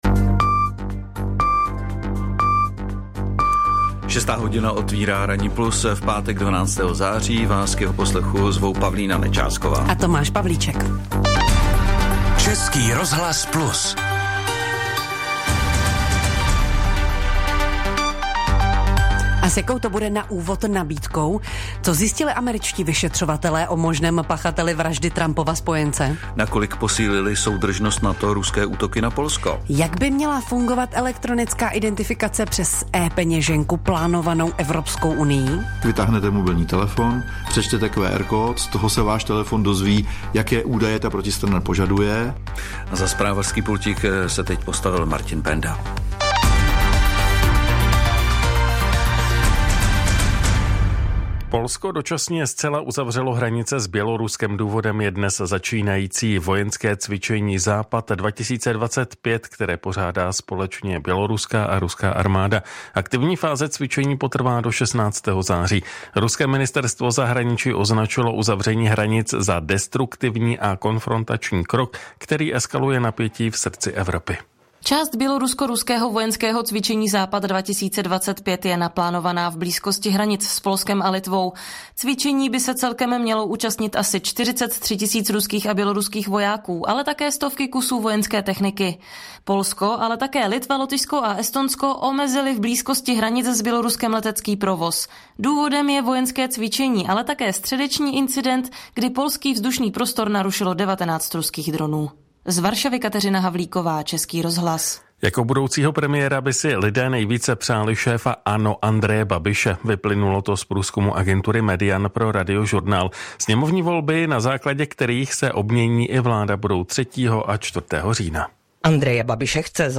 ČRo Plus – rozhovor